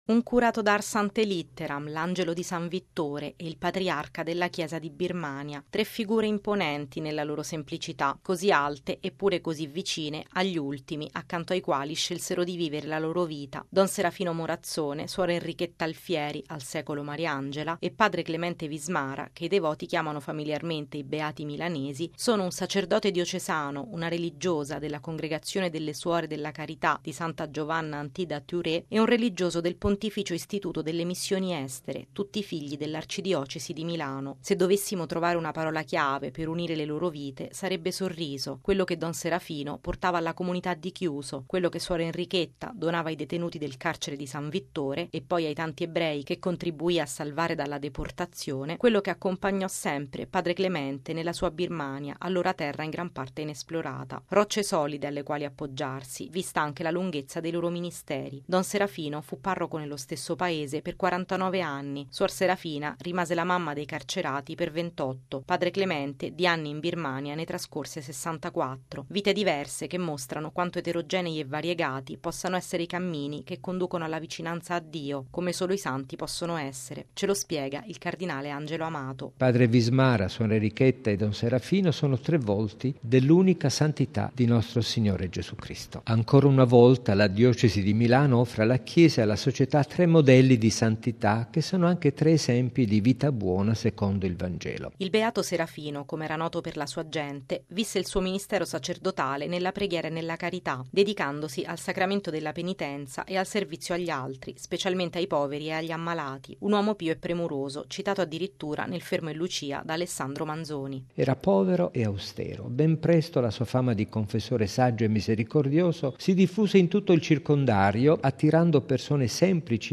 A presiedere la cerimonia, il legato pontificio, il cardinale Angelo Amato, prefetto della Congregazione delle Cause dei Santi.